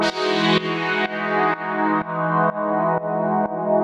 GnS_Pad-MiscA1:4_125-E.wav